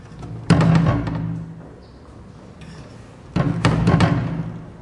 描述：悬疑，管弦乐，惊悚片
Tag: 惊悚 悬念 管弦乐